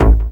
BAS_Seq  Beat.wav